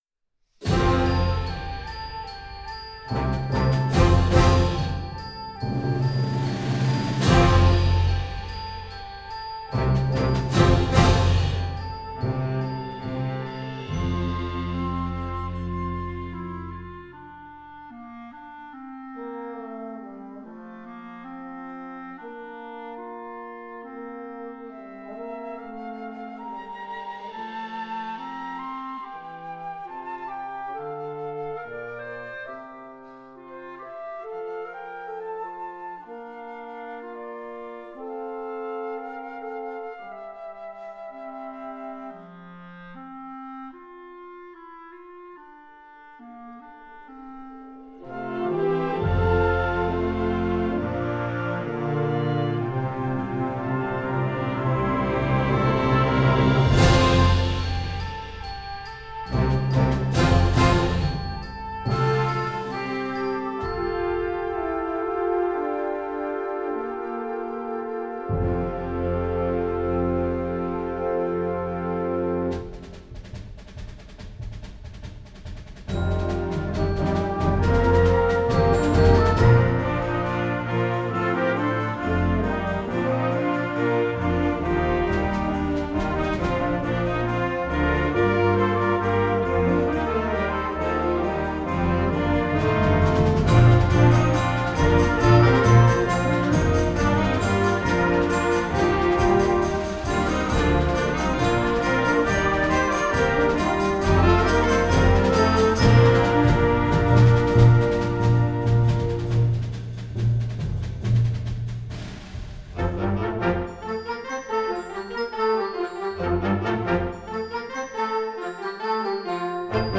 Gattung: Ouvertüre für Jugendblasorchester
Besetzung: Blasorchester